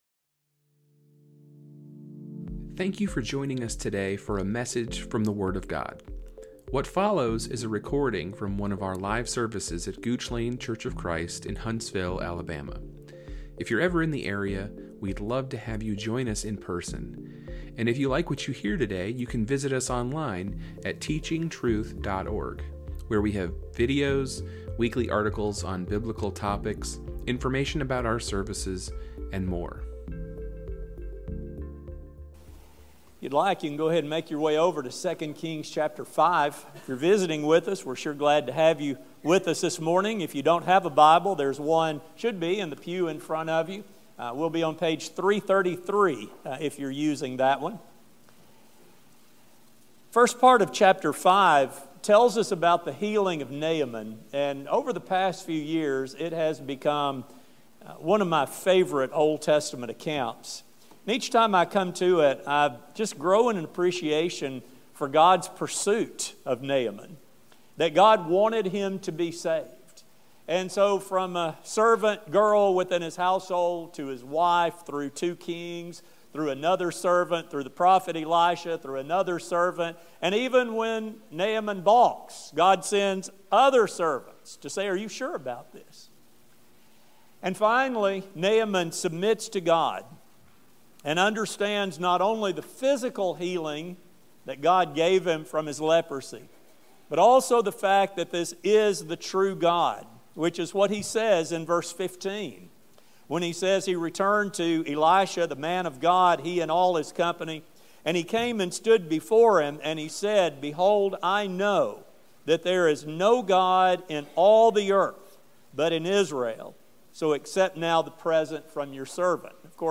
This study will focus on the ungodly attitudes of Elisha’s servant Gehazi which illustrate dangerous attitudes that can still be present today. A sermon